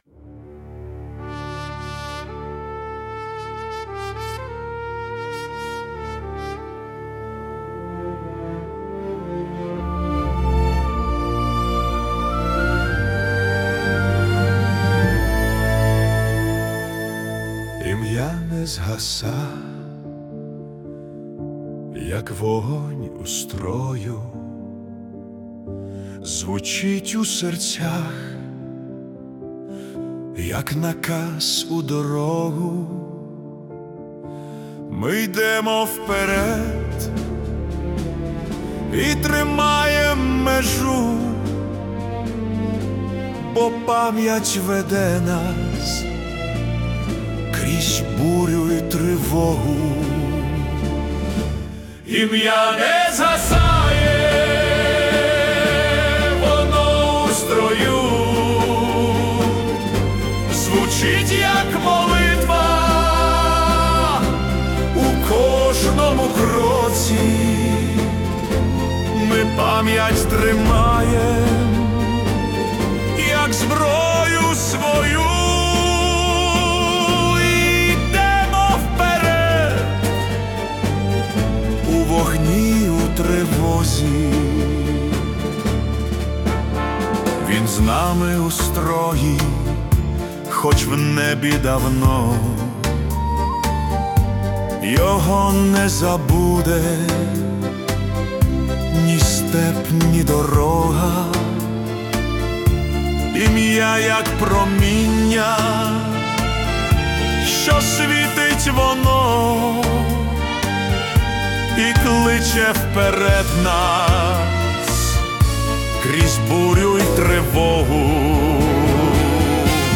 🎵 Жанр: Ukrainian Heroic March
урочистий військовий марш (110 BPM)